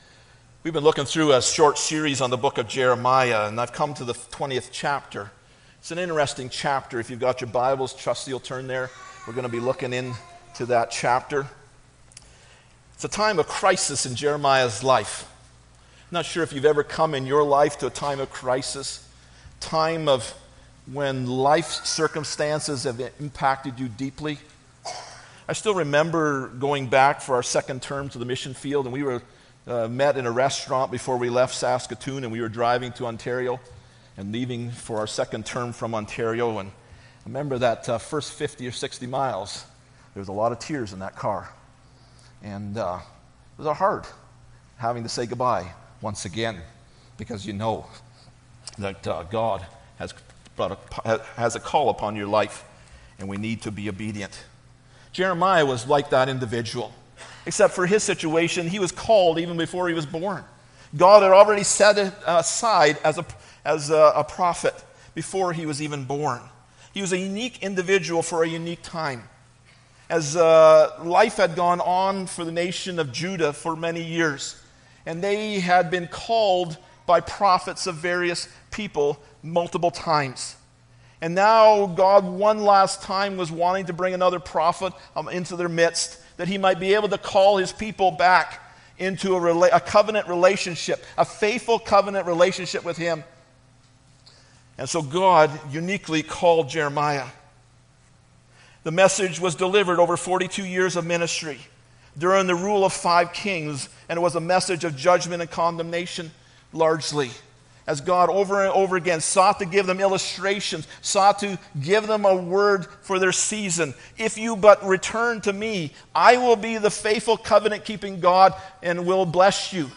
Jeremiah 20:7-13 Service Type: Sunday Morning Bible Text